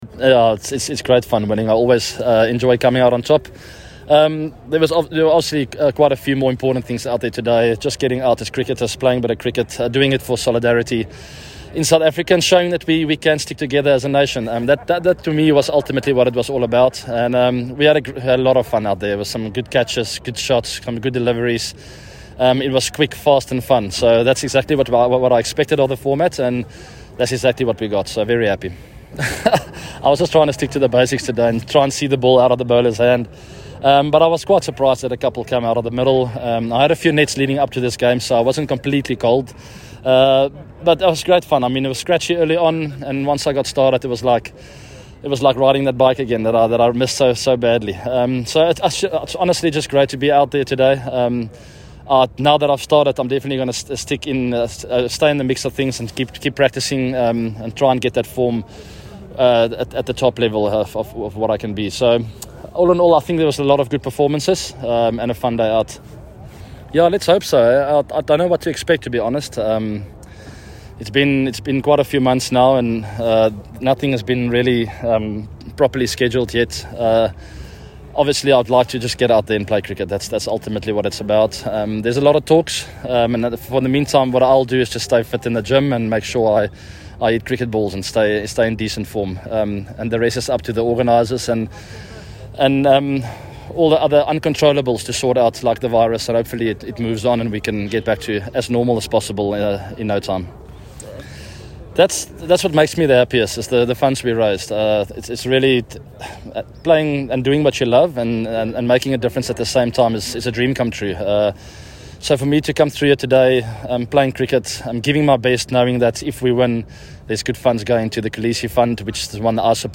Eagles captain AB de Villiers speaks to the media after winning the inaugural Solidarity Cup